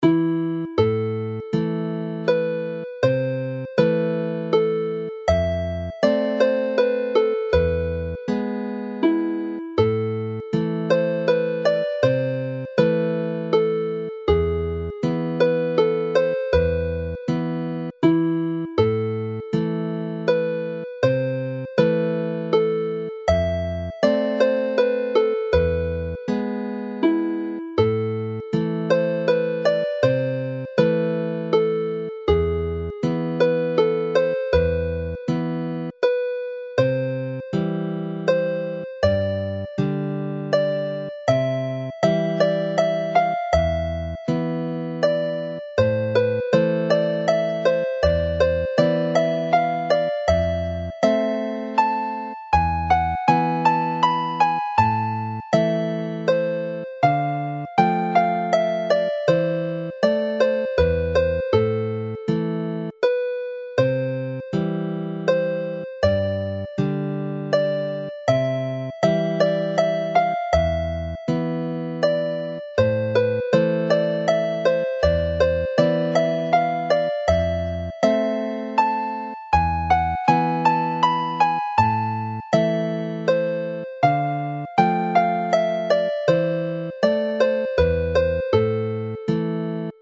All three melodies are in the Welsh A minor which use a G# in the scale, giving an E major chord rather than the E minor generally found in Irish and Scottish tunes.
Melody and accompaniment